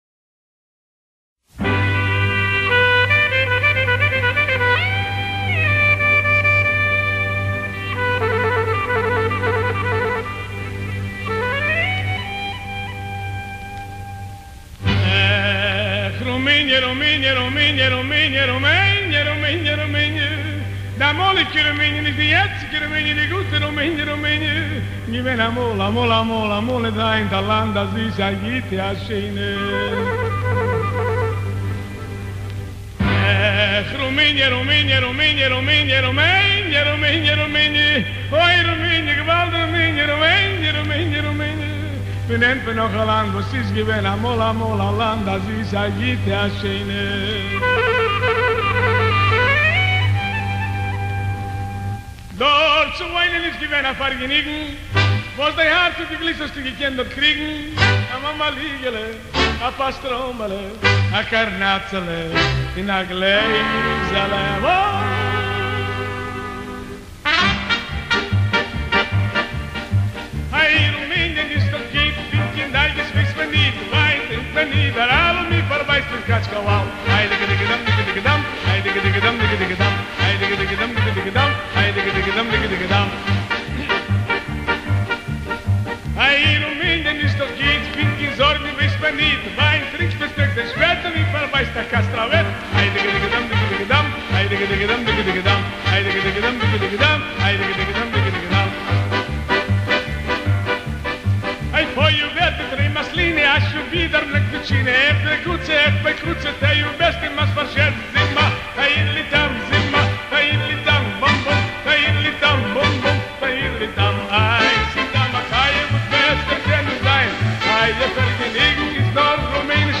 listen to the intro, and the hai di gi di gi dees